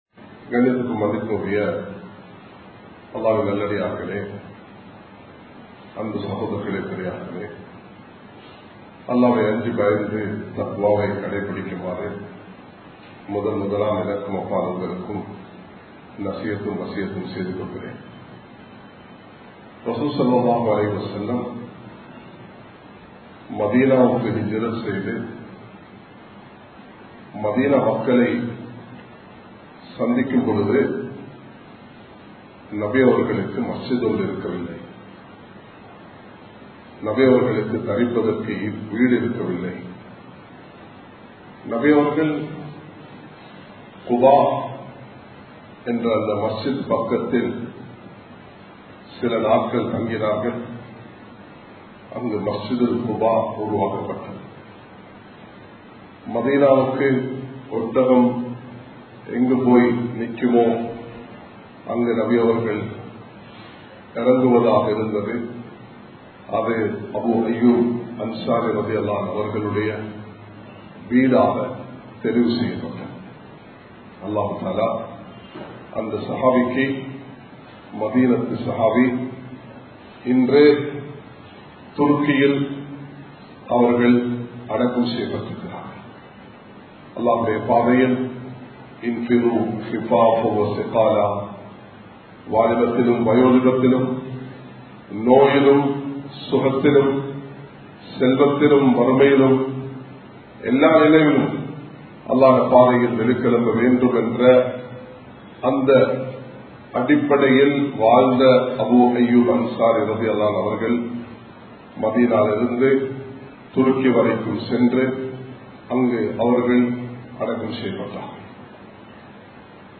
எம் நாட்டைக் கட்டியெழுப்புவோம் (Let's Bring up our country) | Audio Bayans | All Ceylon Muslim Youth Community | Addalaichenai
Colombo 11, Samman Kottu Jumua Masjith (Red Masjith)